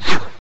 sk_throw.wav